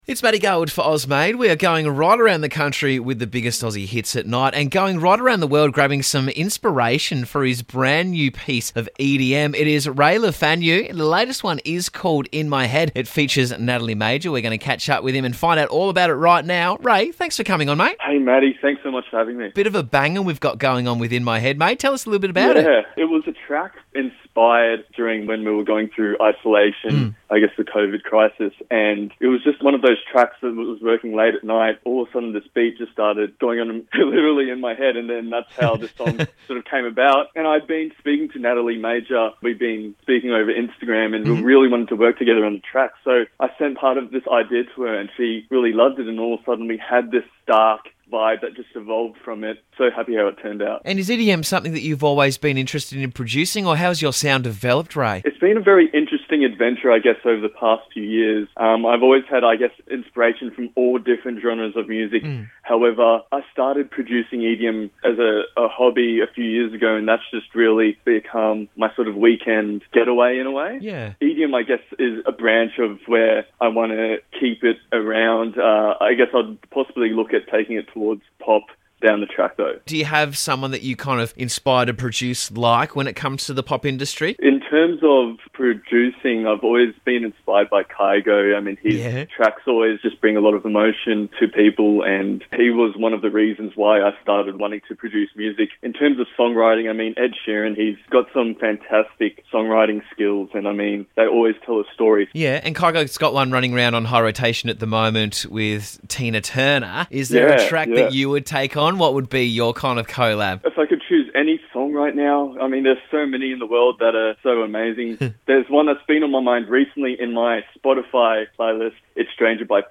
emotional, atmospheric brand of EDM